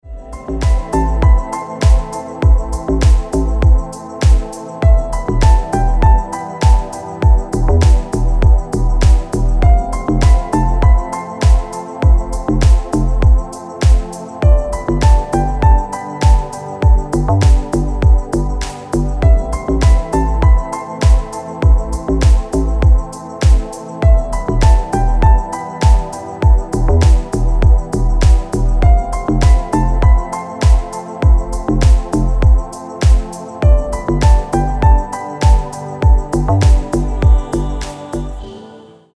deep house
спокойные
релакс
эхо
Красивая расслабляющая музыка